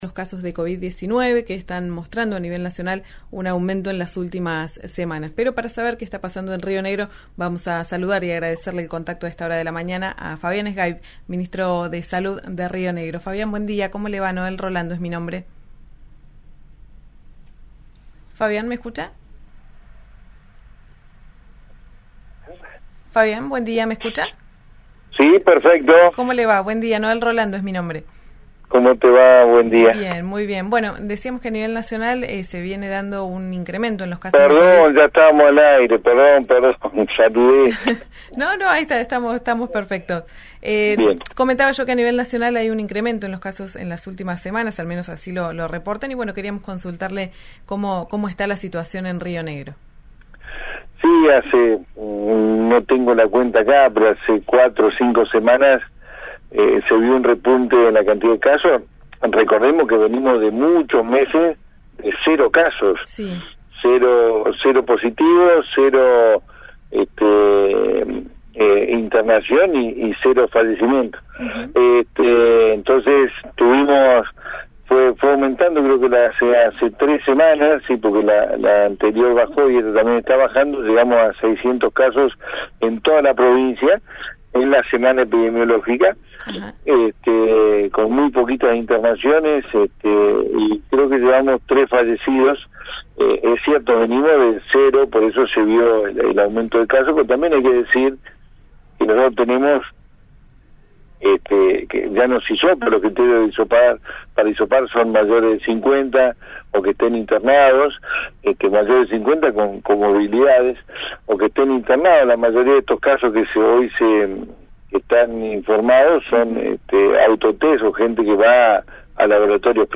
El ministro de Salud de Río Negro, Fabián Zgaib, dialogó con RÍO NEGRO RADIO.
Escuchá Fabián Zgaib en diálogo con «Ya Es Tiempo» por RÍO NEGRO RADIO: